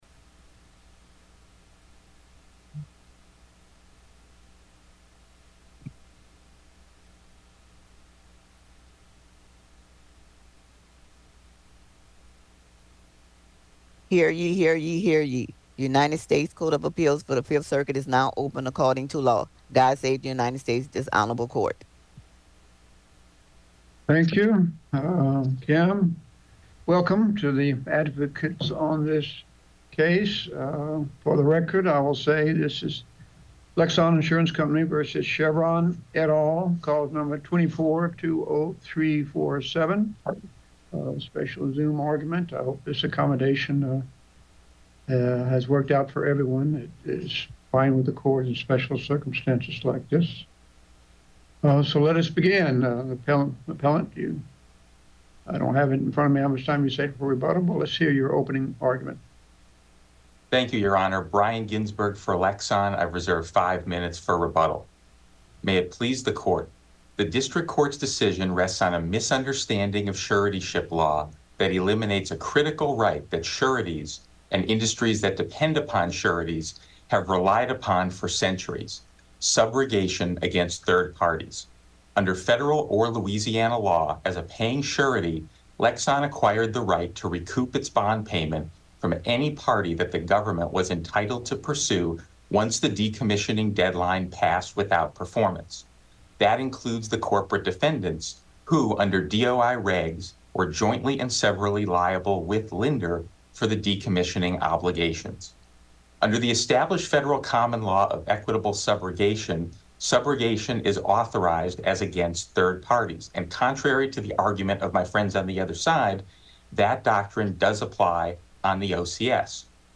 Oral Argument Recordings